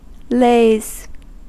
Ääntäminen
Ääntäminen US Tuntematon aksentti: IPA : /leɪz/ Haettu sana löytyi näillä lähdekielillä: englanti Käännöksiä ei löytynyt valitulle kohdekielelle.